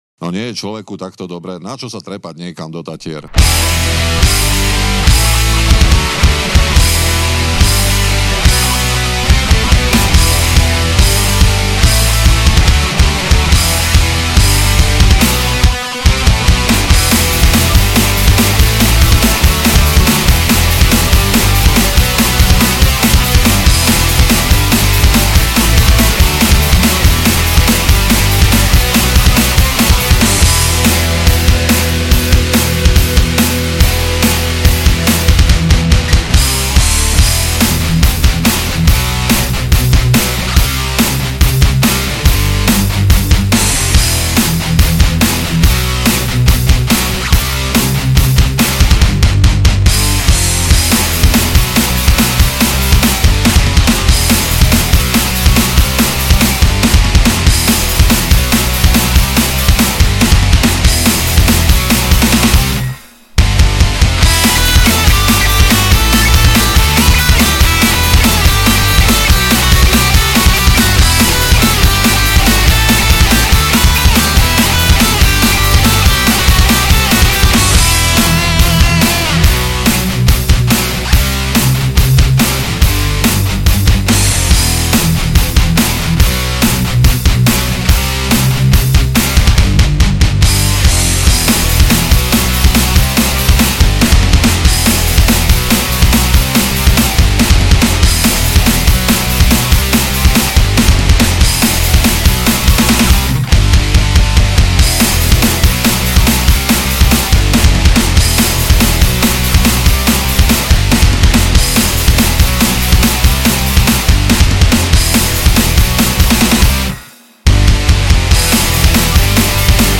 Žánr: Rock
basgitara
gitara